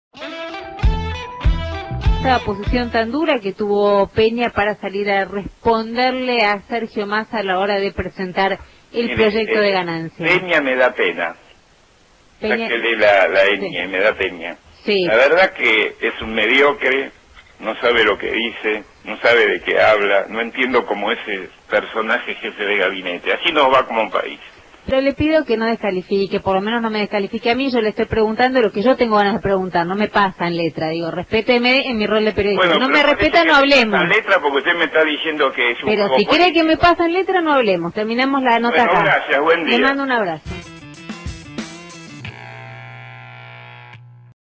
Aldo Pignanelli, el expresidente del Banco Central tuvo un fuerte cruce con la periodista Romina Manguel, se enojó y cortó la comunicación telefónica.